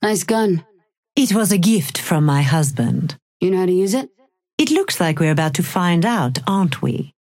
Holliday and Lady Geist conversation 1